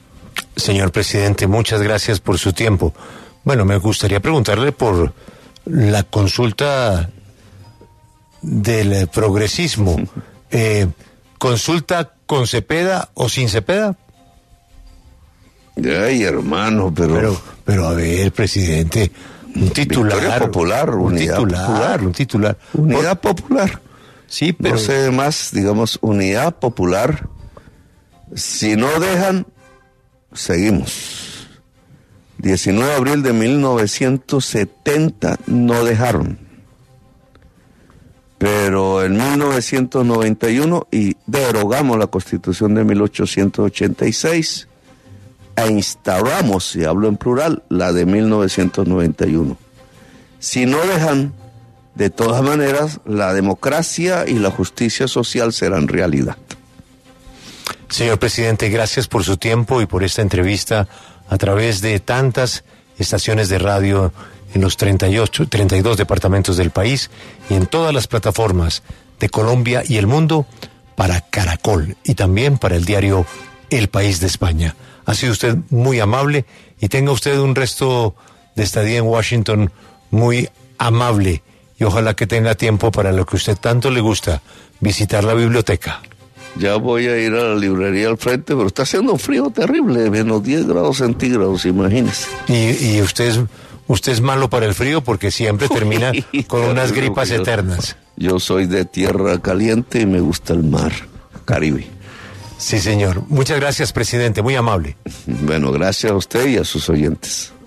Luego de terminada su reunión en la Casa Blanca con Donald Trump, el presidente de Colombia sostuvo una entrevista exclusiva en Caracol Radio, con Julio Sánchez Cristo.
“Si no dejan, de todas maneras la democracia y la justicia social serán realidad”, señaló Petro, en un tono conciliador, frente al panorama actual que tiene a Barreras y Cepeda encabezando la disputa por movilizar el capital político desde antes de las elecciones.